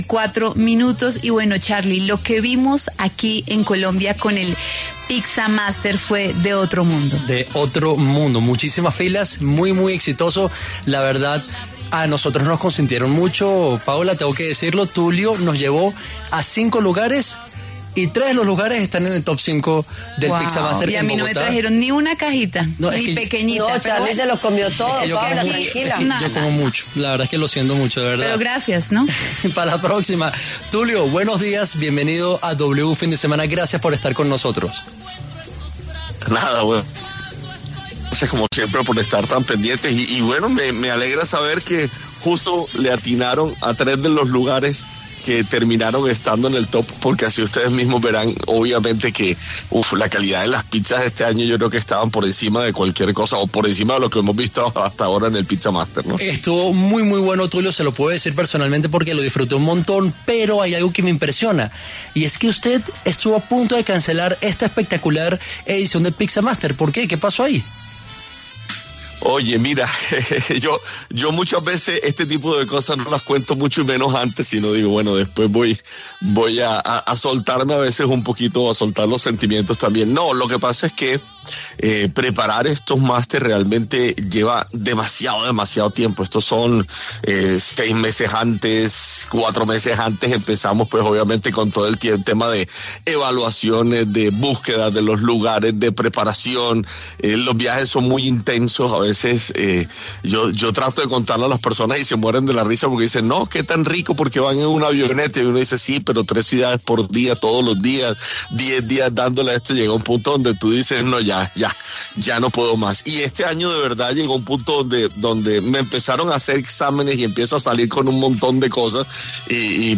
Tulio Zuloaga, más conocido como ‘Tulio Recomienda’ habló en los micrófonos de W Fin de Semana y entregó detalles de lo que fue el Pizza Máster 2025 y el riesgo que tuvo de no poder realizar esta última edición del concurso gastronómico.